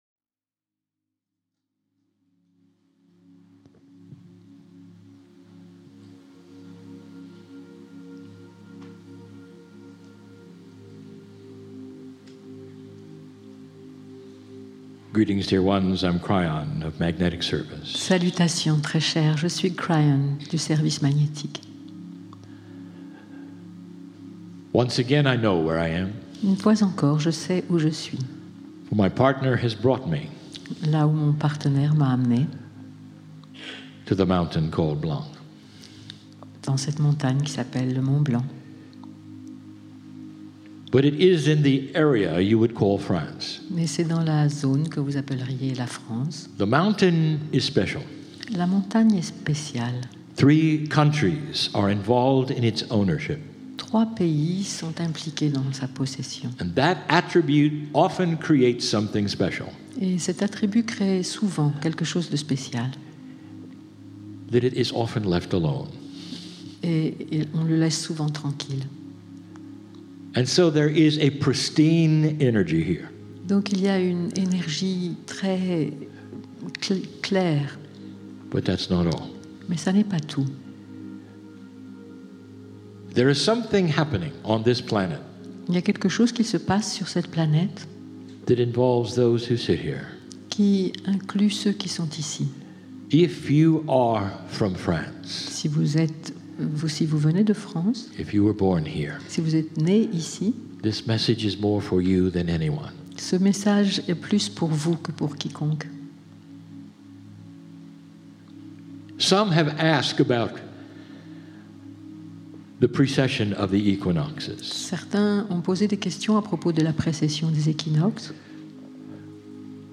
KRYON CHANNELLING CHAMONIX FRANCE 1